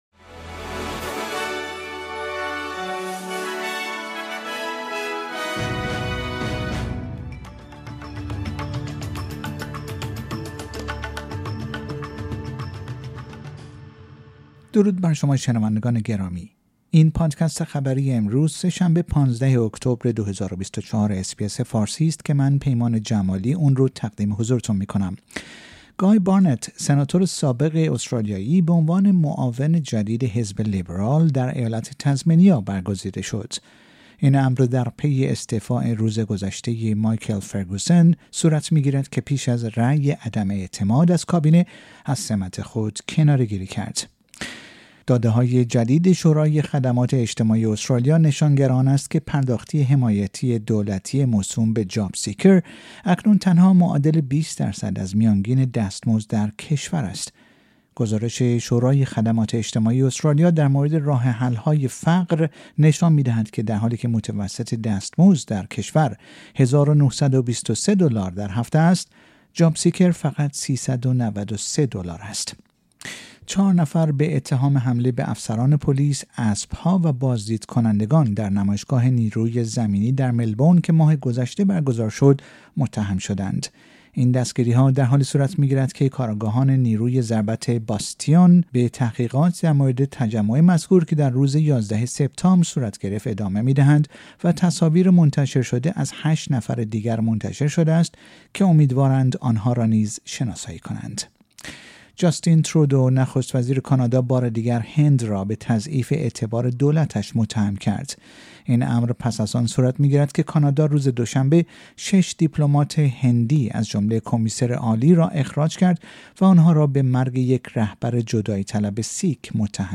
در این پادکست خبری مهمترین اخبار استرالیا در روز سه شنبه ۱۵ اکتبر ۲۰۲۴ ارائه شده است.